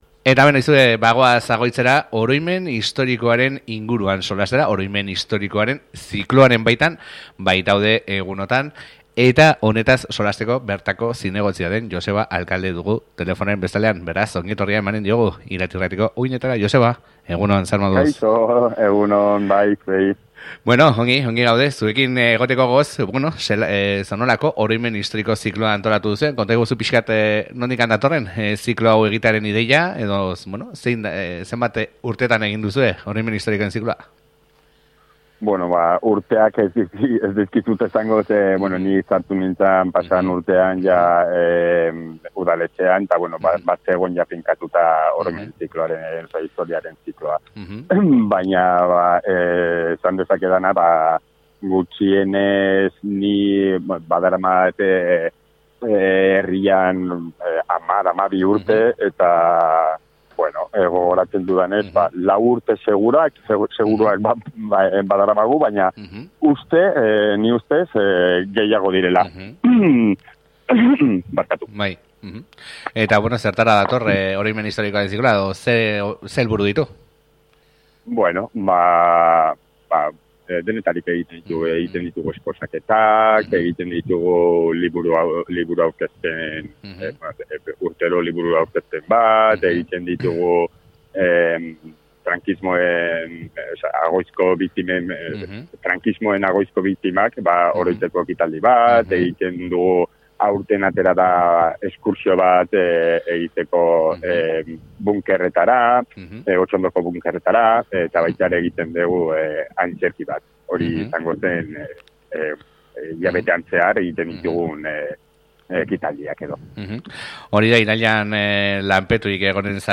Honen inguruan solastu gara Agoitzeko zinegotzia den Joseba Alcalderekin.